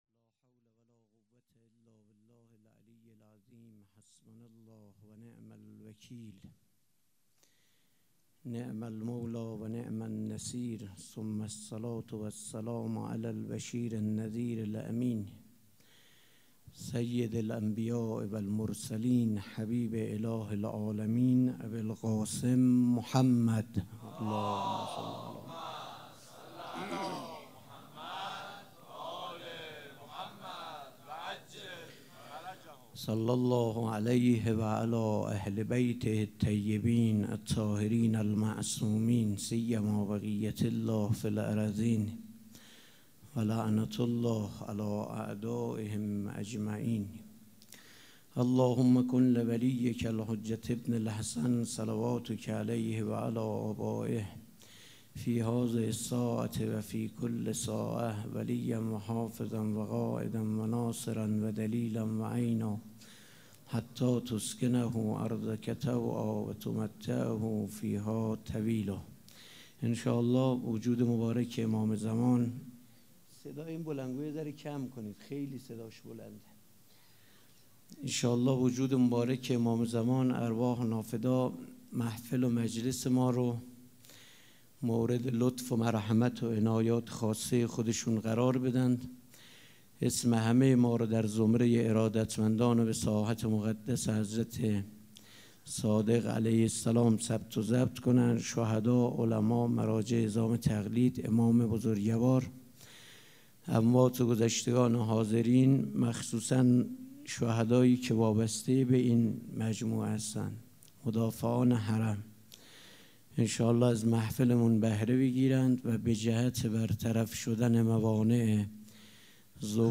سخنرانی
شب شهادت امام صادق علیه السلام جمعه هفتم تیر ماه ١٣٩۸ حسینیه ی ریحانه الحسین
سخنرانی.mp3